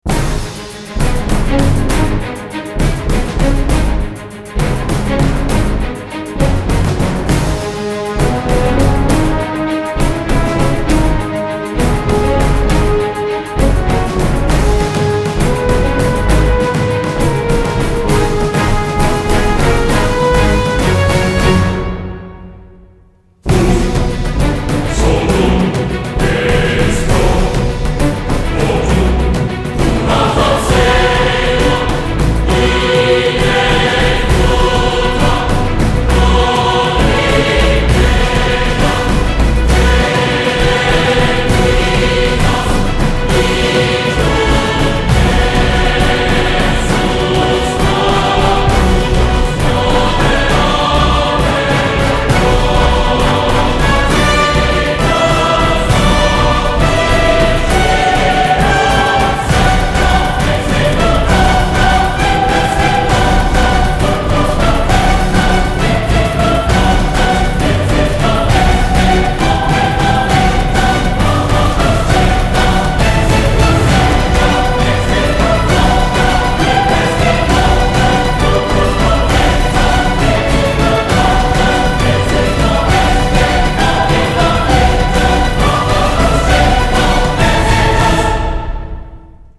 C'est dans une vidéo que j'ai, on dirait une musique de film.